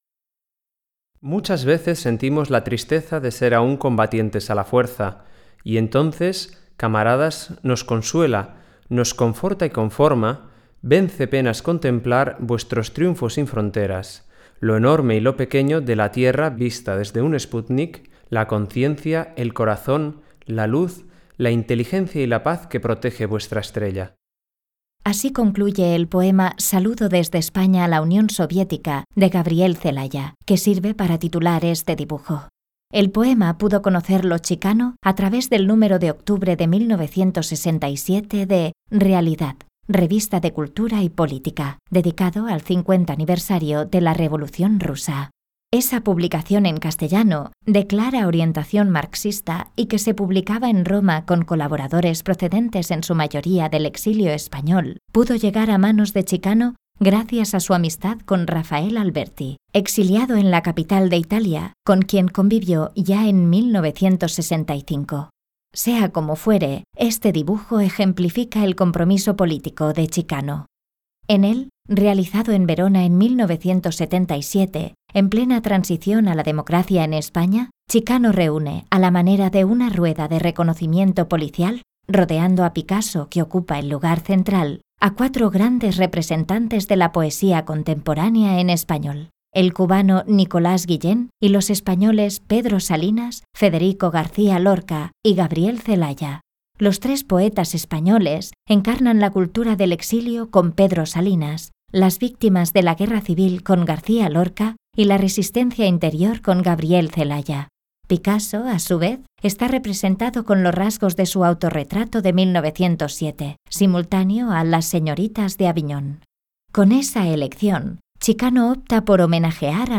Audioguías